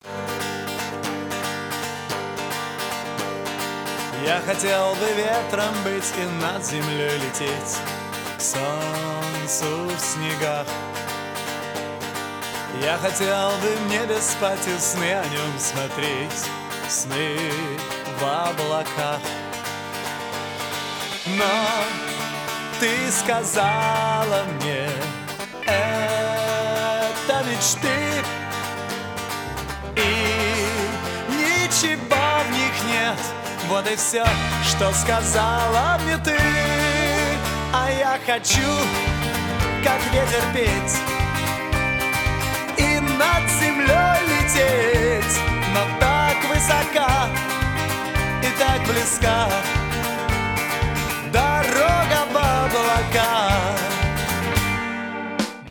• Качество: 320, Stereo
гитара
мужской вокал
русский рок
ретро